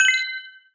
Game Notification 82.wav